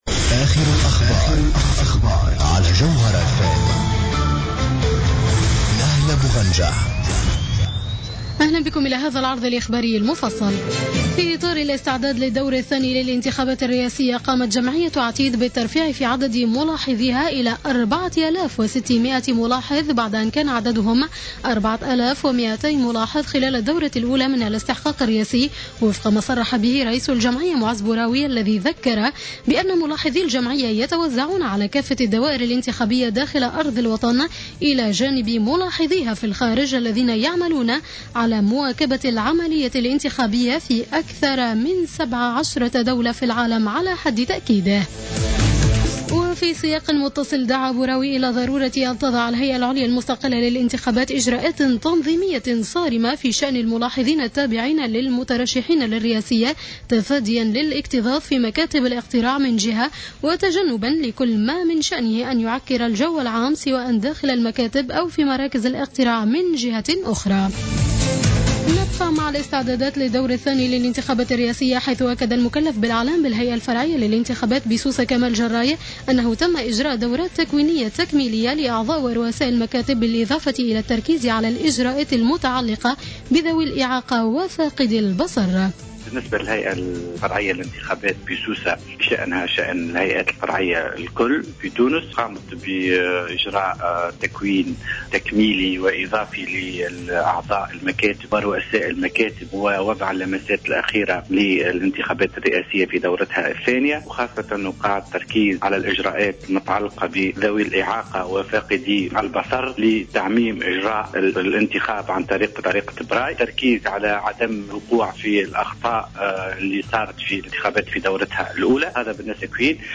نشرة أخبار السابعة مساء ليوم الأحد 14-12-14